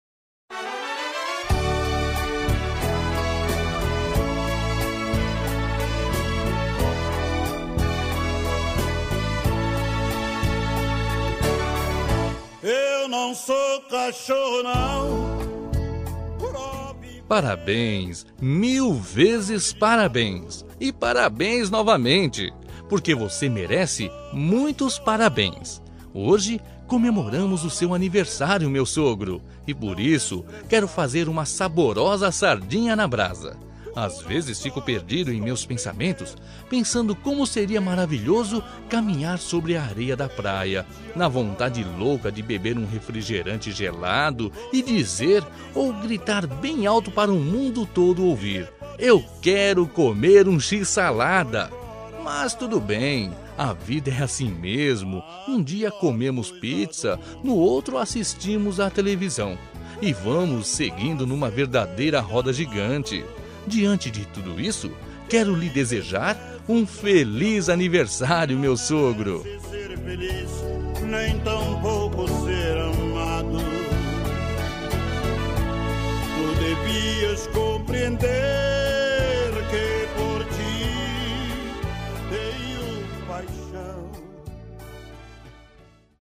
Aniversário de Humor – Voz Masculina – Cód: 200217